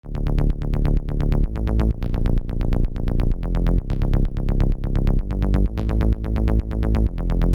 Вообще я такой бас написал на нексусе.